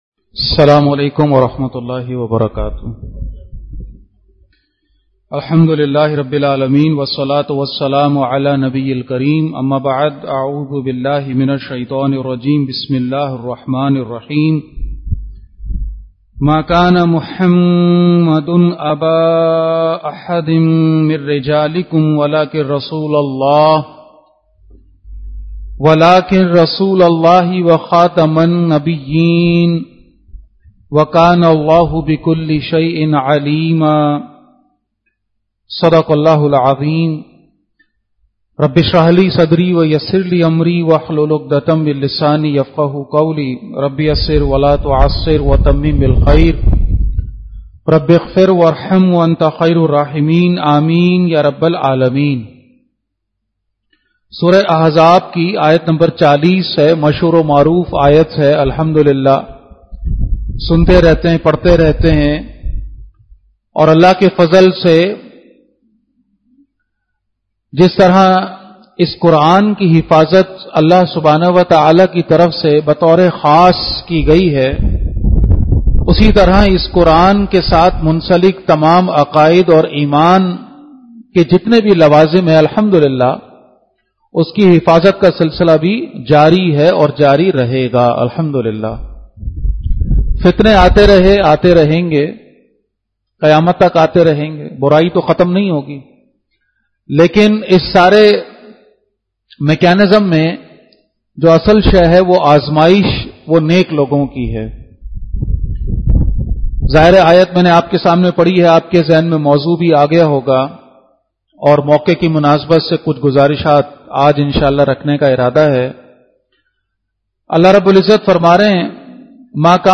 Khutbat-e-Jummah (Friday Sermons)
@ Masjid Jame Al-Quran, Quran Institute Johar. 2024-09-06 Khatm-e-Nabuwat aur Humari Zimedariyan , ختم نبوت اور ہماری ذمہ داریاں۔